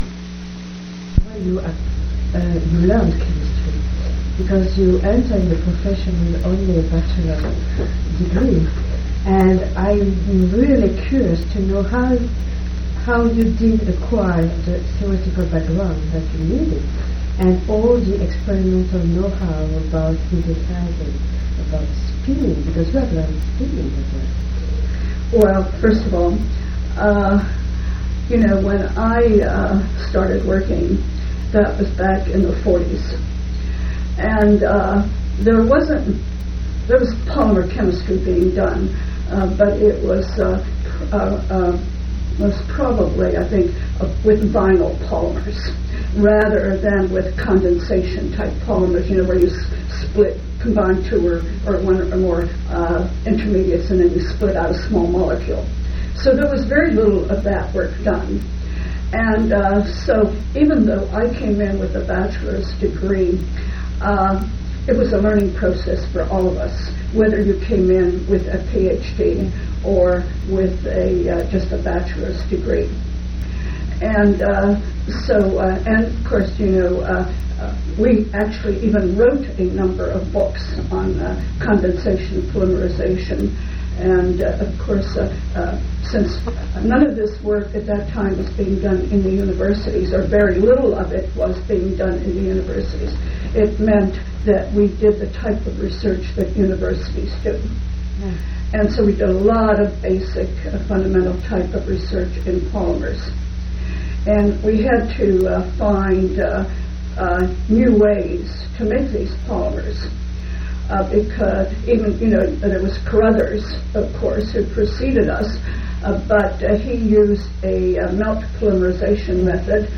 Place of interview Delaware--Wilmington
Genre Oral histories